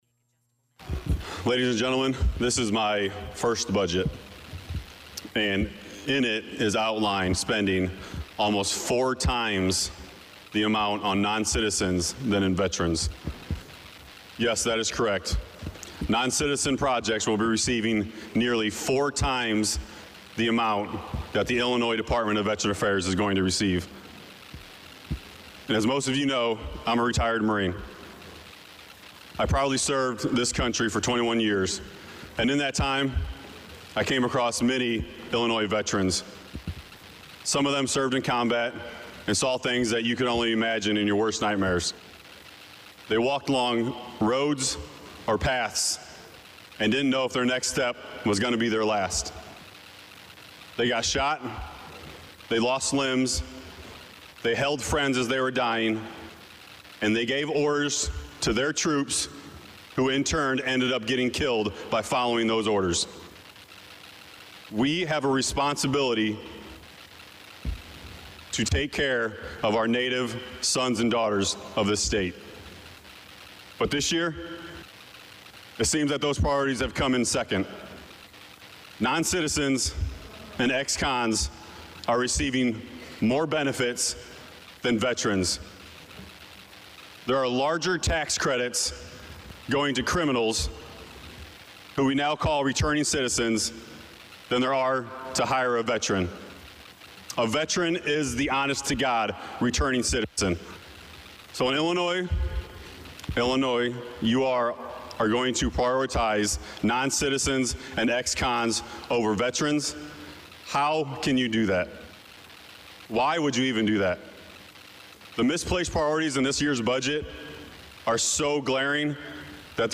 The following are comments from State Representative Brandun Schweizer (R-Danville).  In his comments, the representative talked about the lack of dollars for veterans affairs.